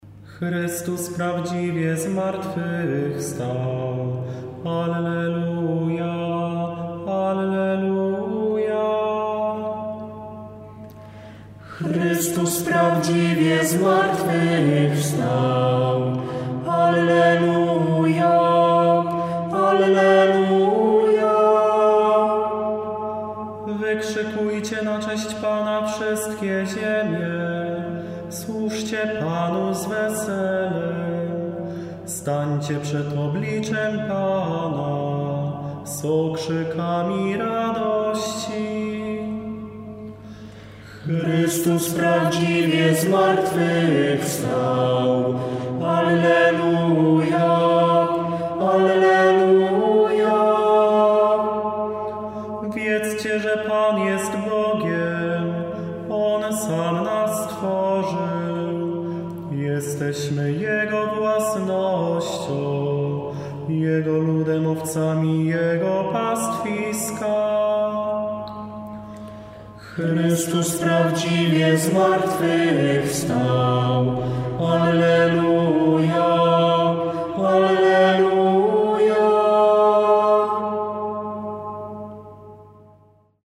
PSALM WEZWANIA